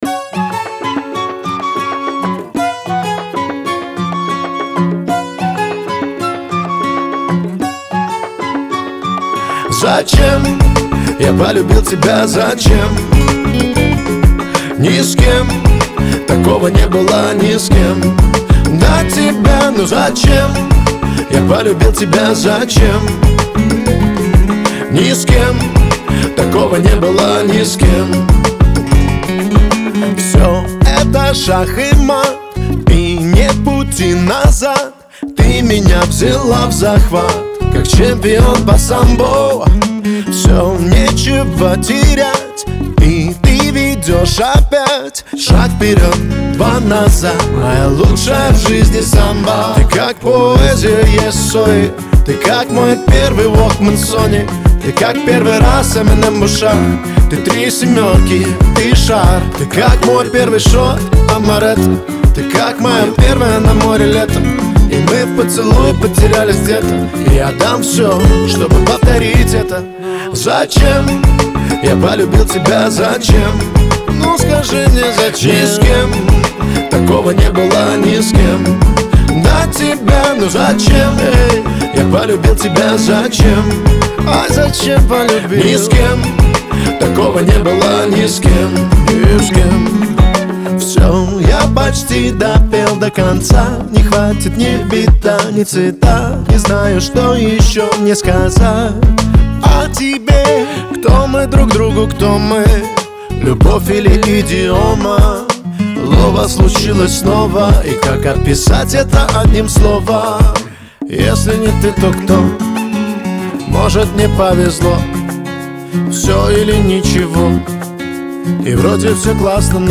это эмоциональный трек в жанре поп